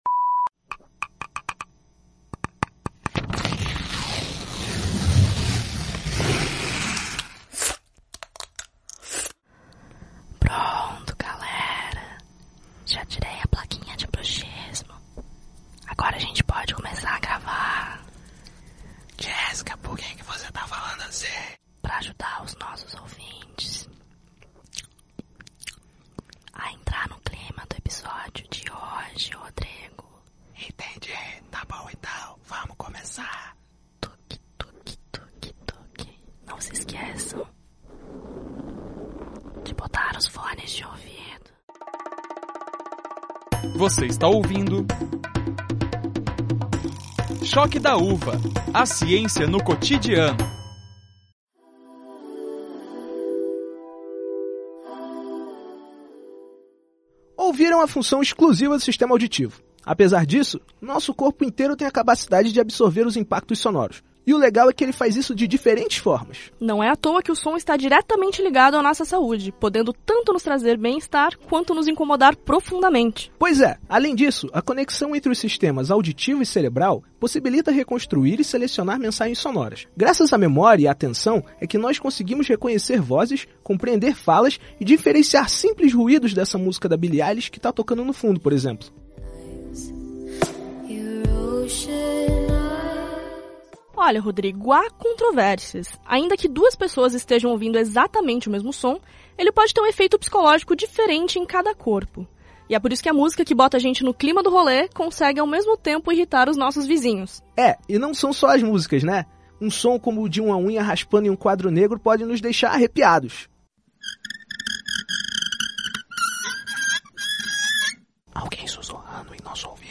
Neste episódio, falamos com quem entende do assunto para explicar como funciona a escuta, quais as partes do corpo envolvidas neste processo e, claro, com experts em ASMR para explicar os segredinhos por trás desses vídeos que fazem tanto sucesso.